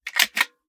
12ga Pump Shotgun - Load Shells 001.wav